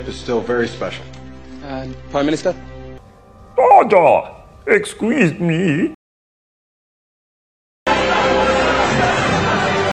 1 It is the Debate Scene where David (Hugh Grant) debates the President (Billy Bob Thornton). The Clip of Hugh Grant doing a Star Wars Impressions comes from the movie Heretic where Mr. Reed (Hugh Grant) argues about religion with using Star wars.